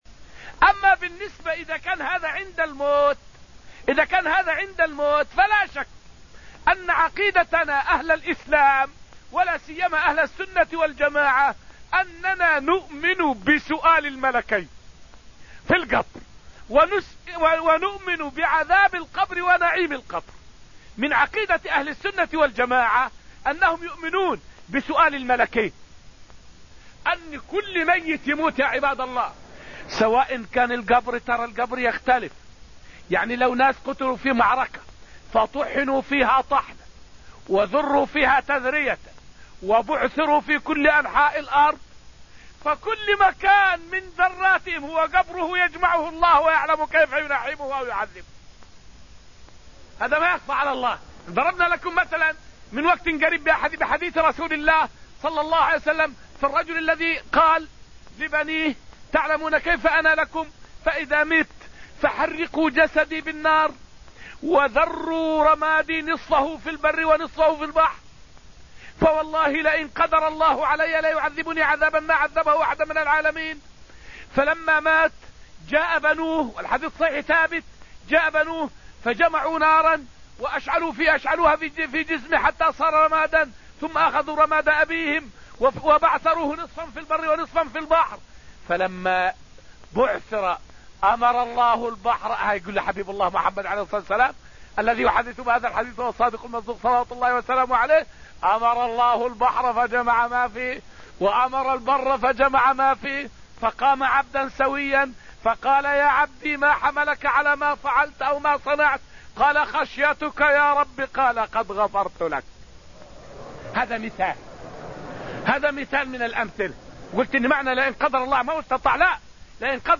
فائدة من الدرس التاسع من دروس تفسير سورة الواقعة والتي ألقيت في المسجد النبوي الشريف حول الإيمان بسؤال الملكين وعذاب القبر ونعيمه.